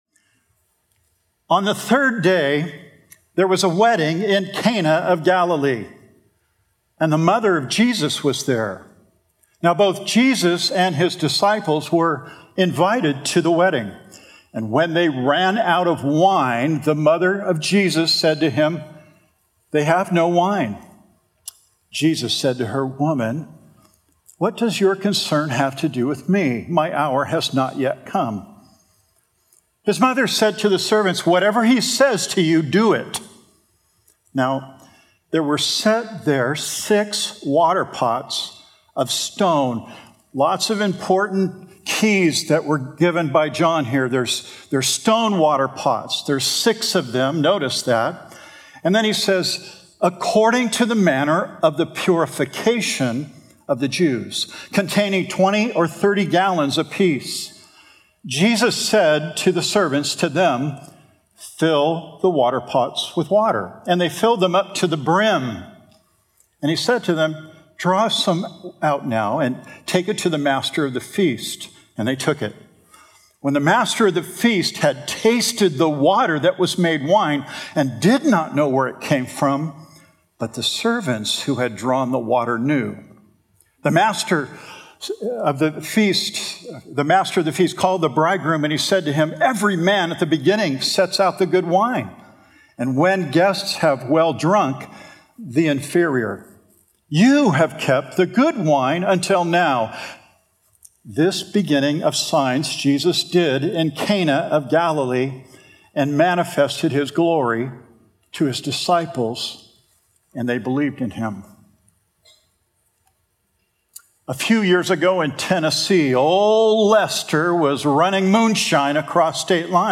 A verse-by-verse sermon through John 2:1-11